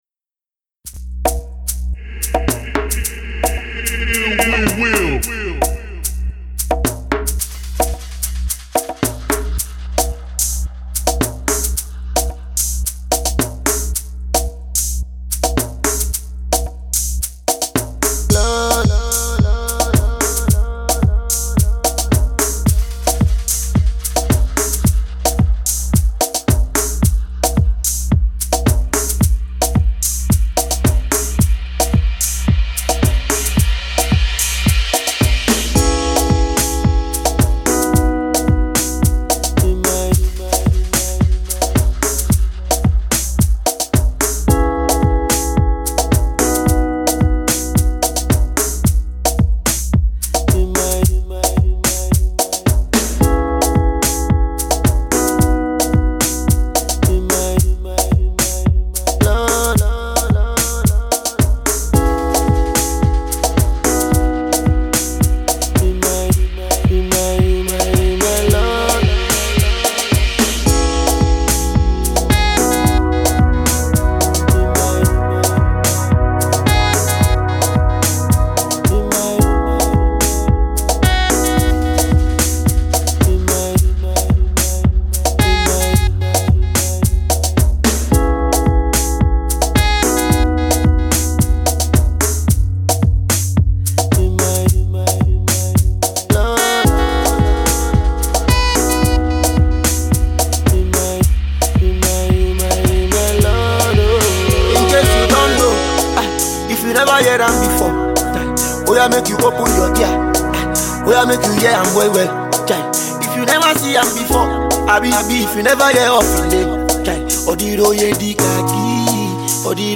A South African house remix